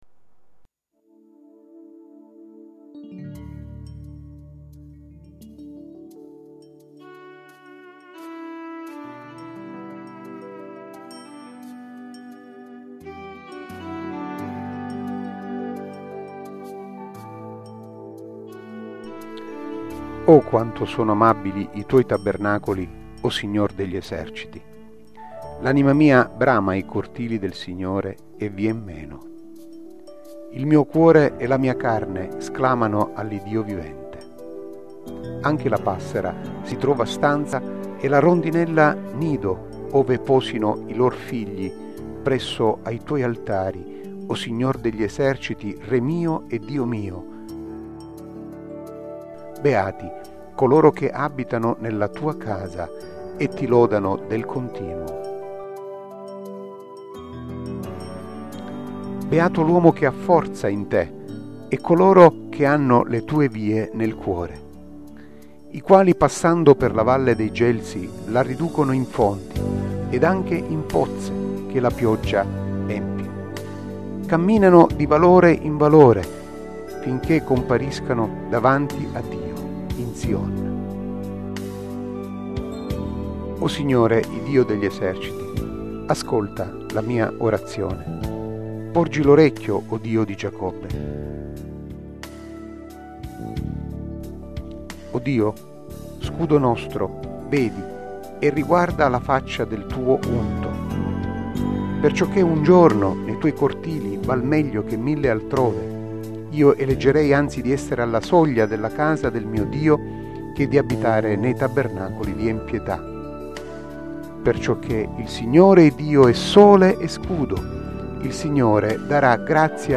Letture della Parola di Dio ai culti della domenica